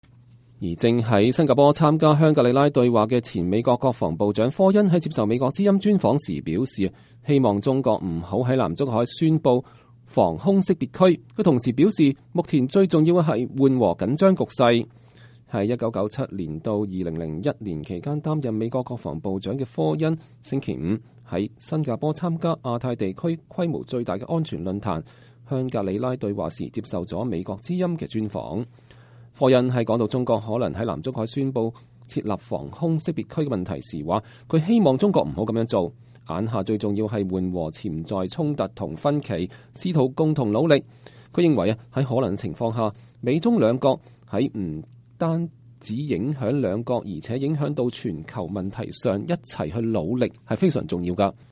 正在新加坡參加香格里拉對話的前美國國防部長科恩在接受美國之音專訪時表示，希望中國不要在南中國海宣佈防空識別區。
在1997到2001年期間擔任美國國防部長的科恩(William Cohen)星期五在新加坡參加亞太地區規模最大的安全論壇--香格里拉對話時接受了美國之音的專訪。